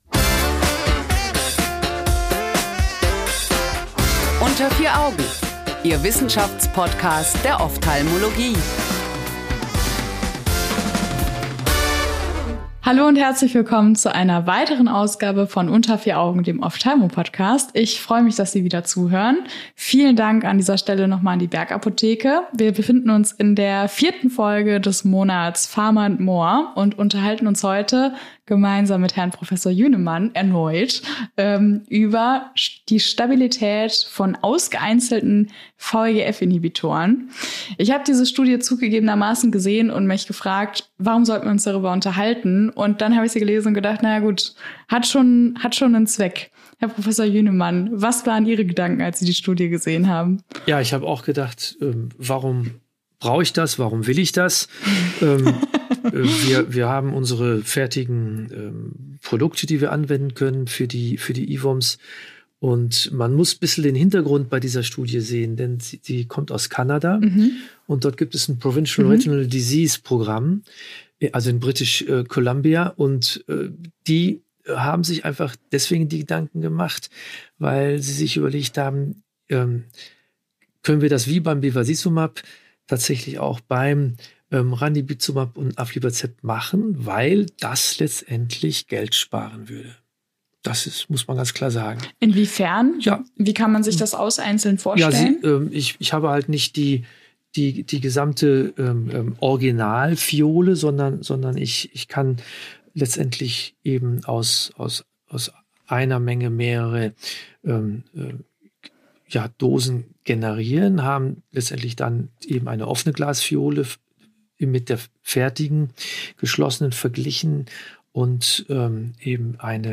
Freuen Sie sich über ein aufschlussreiches Gespräch!